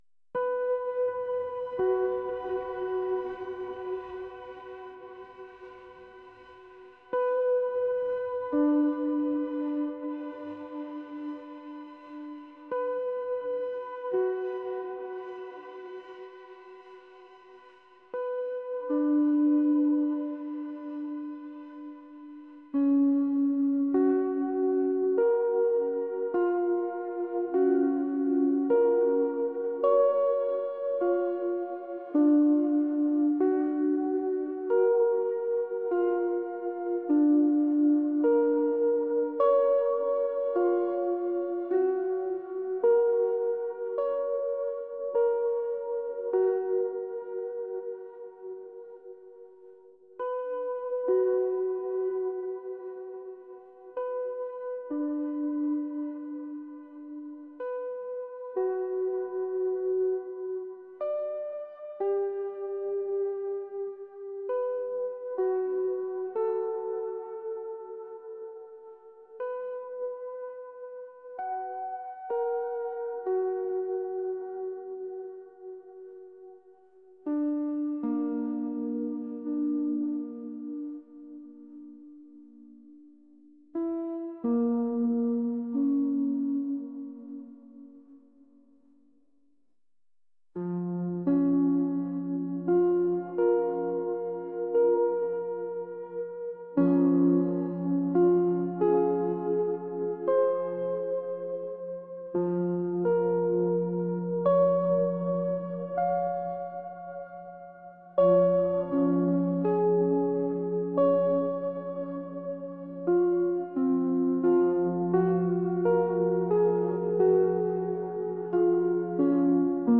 ethereal | atmospheric | pop